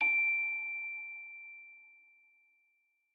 celesta1_13.ogg